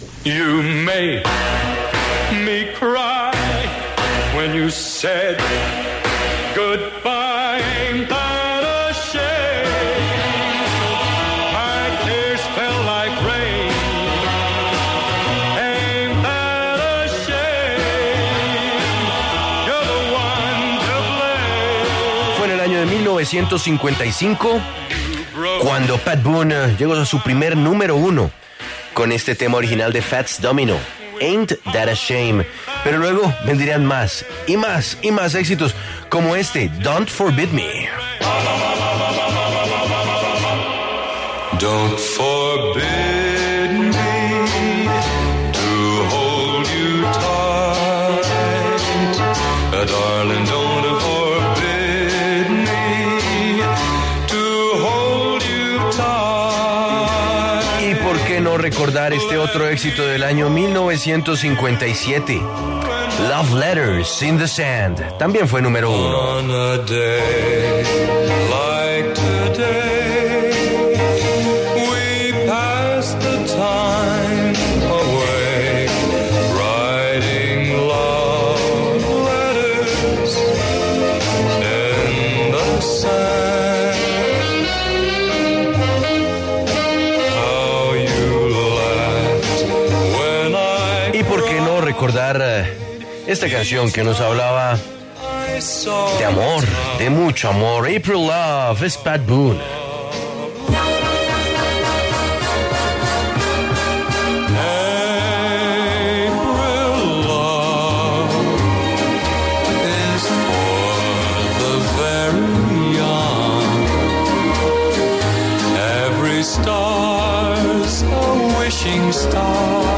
Pat Boone, cantante, compositor, actor y escritor estadounidense, habló en La W a propósito de la celebración de su cumpleaños.